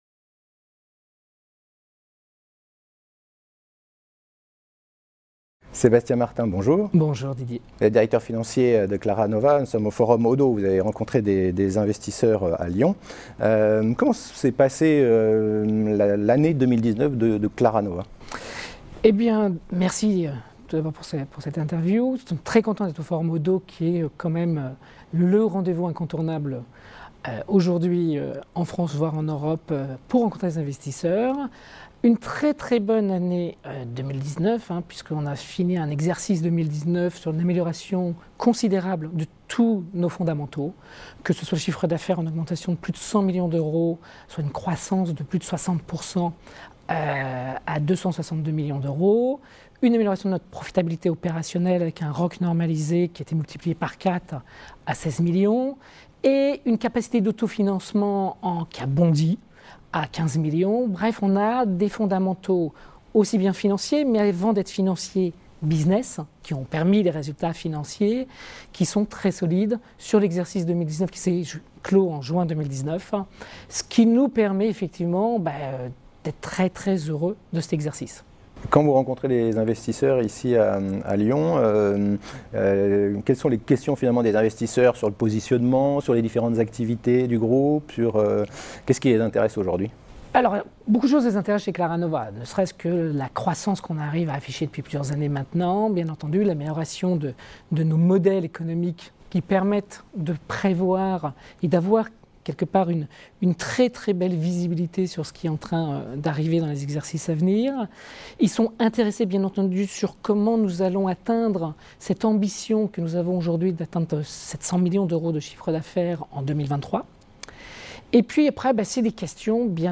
La Web Tv a rencontré les dirigeants au Oddo BHF Forum 2020 qui s'est tenu à Lyon le 9 et le 10 janvier.